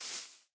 grass6.ogg